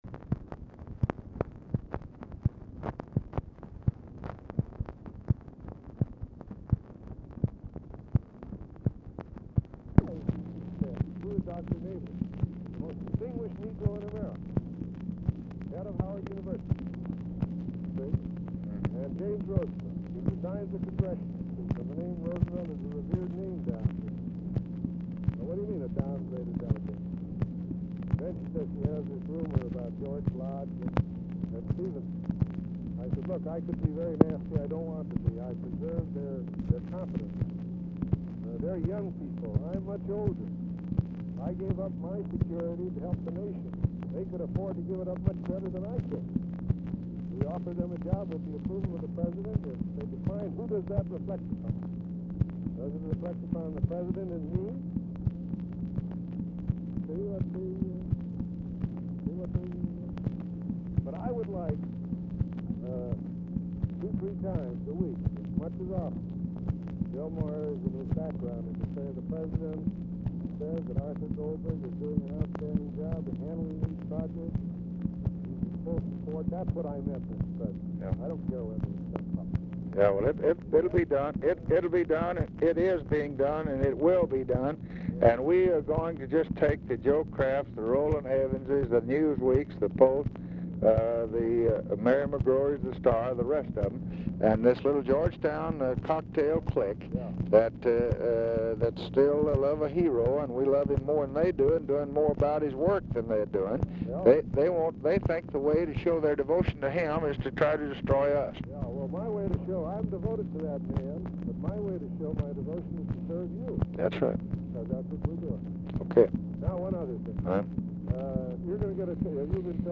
Telephone conversation # 8893, sound recording, LBJ and ARTHUR GOLDBERG, 9/21/1965, 12:40PM | Discover LBJ
Format Dictation belt
Location Of Speaker 1 Oval Office or unknown location
Specific Item Type Telephone conversation